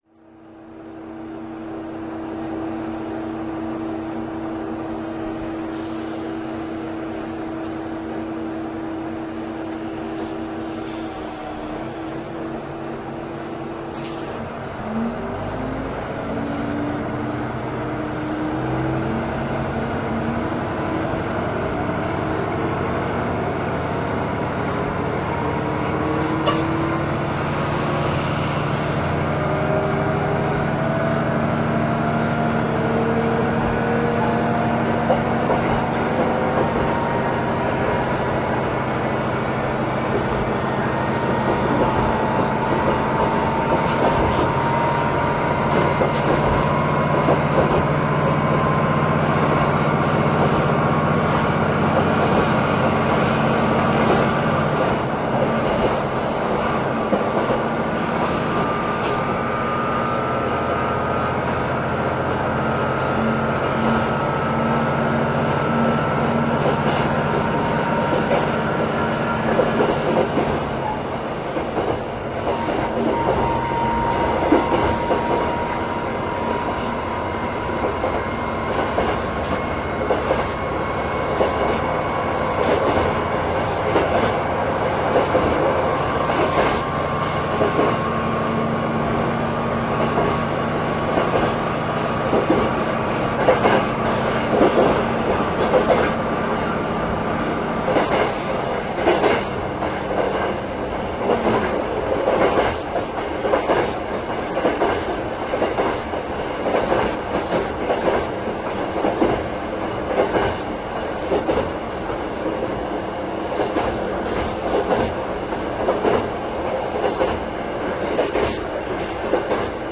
走行音[k125a.ra/333KB]
駆動機関：DMF13HZ(330PS)×1